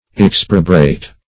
Search Result for " exprobrate" : The Collaborative International Dictionary of English v.0.48: Exprobrate \Ex"pro*brate\, v. t. [L. exprobratus, p. p. of exprobrare; ex out + probrum a shameful or disgraceful act.] To charge upon with reproach; to upbraid.